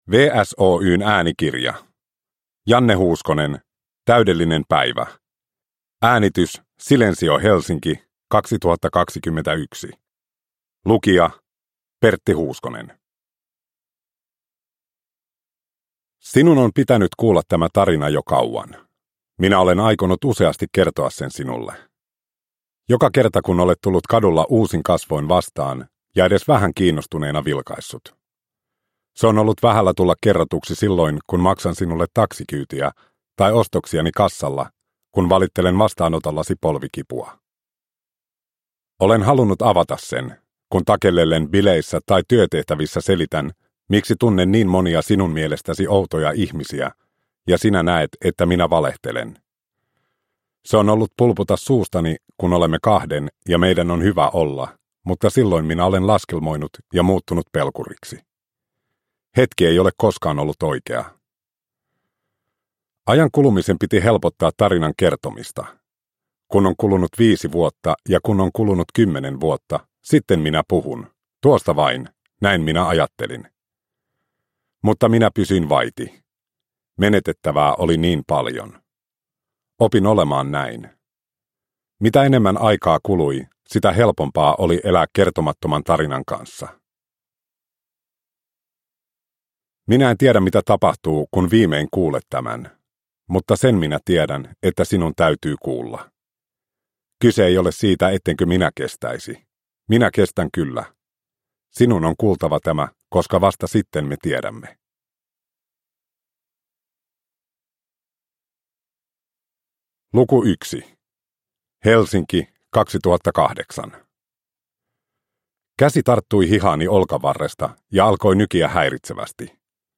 Täydellinen päivä (ljudbok) av Janne Huuskonen